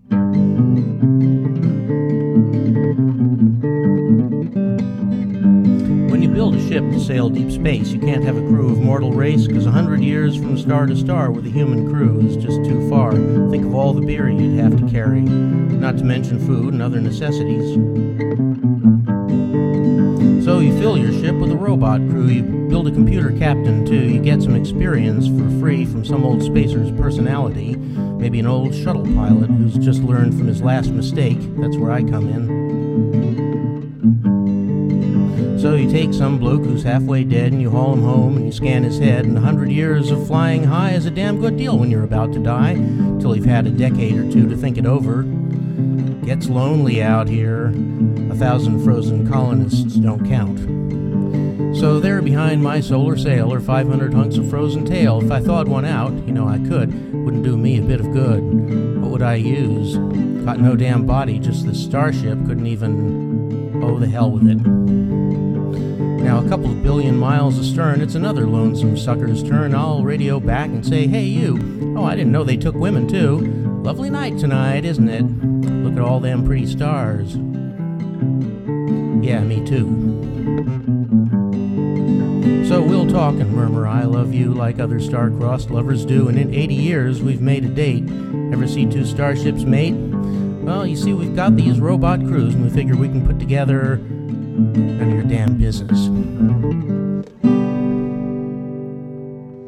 They're not necessarily in any shape to be heard!